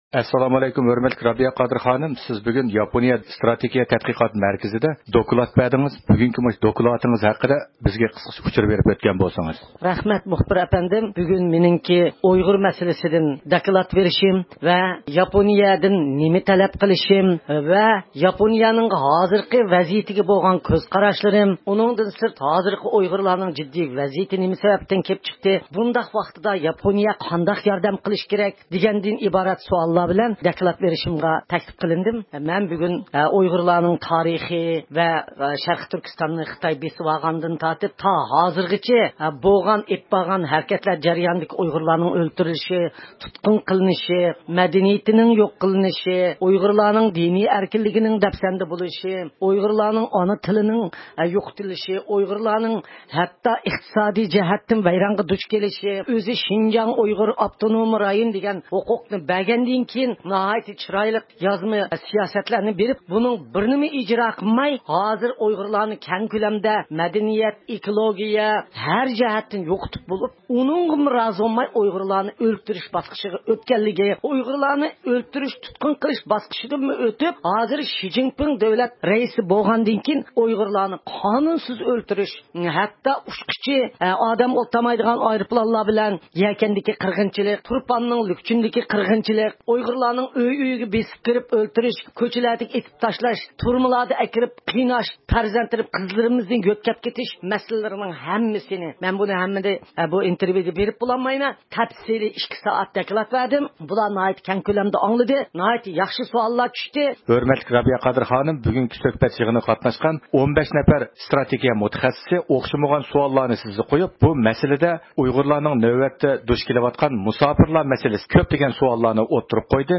بىز دوكلات سۆزلەش پائالىيىتىدىن كېيىن دۇنيا ئۇيغۇر قۇرۇلتىيىنىڭ رەئىسى رابىيە قادىر خانىم بىلەن دوكلاتىنىڭ ئاشكارا مەزمۇنلىرى ھەققىدە سۆھبەت ئېلىپ باردۇق.